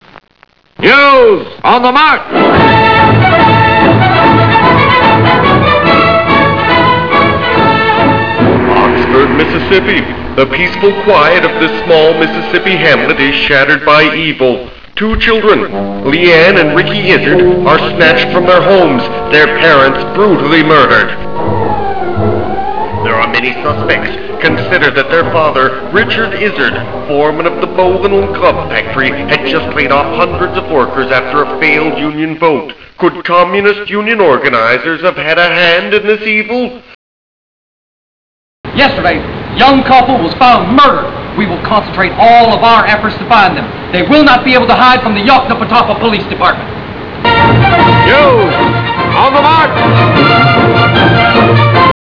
1958 cinema newsreel footage includes Oxford Eagle headline, crime scene photo, and snippet from a press conference.